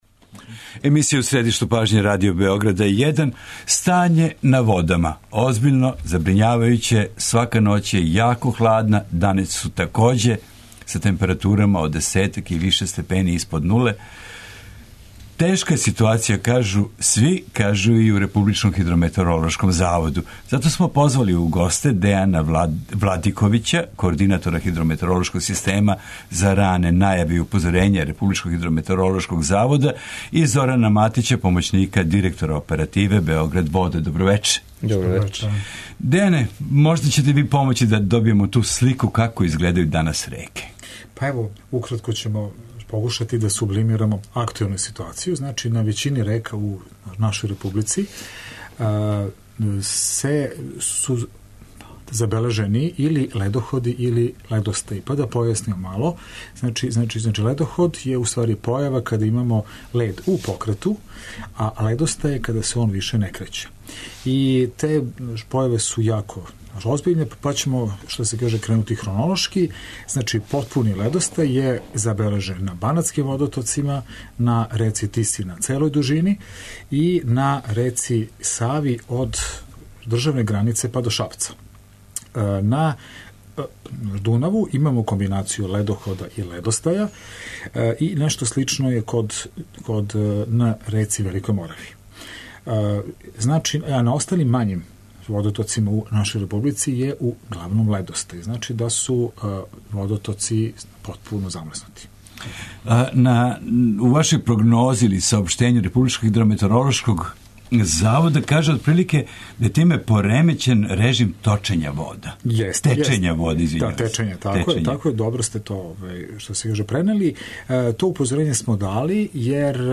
Током емисије чућемо и наше репортере који ће бити на обалама залеђених река.
доноси интервју